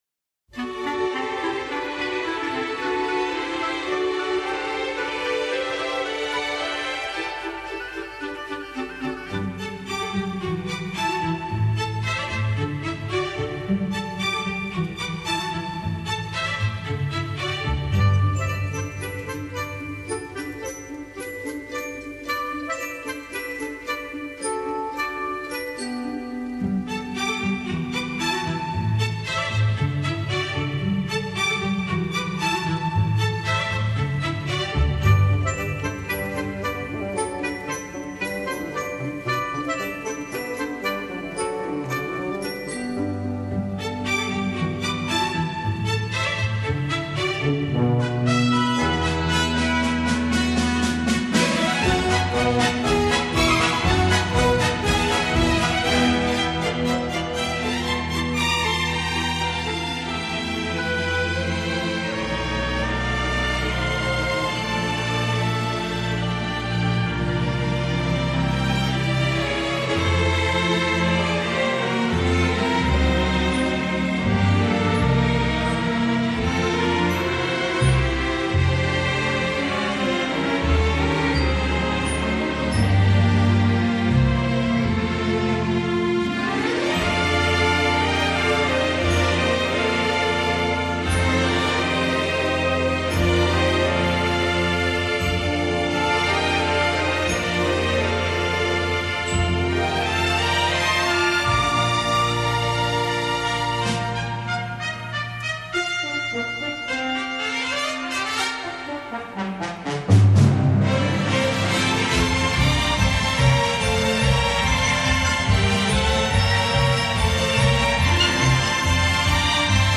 Genre: easy listening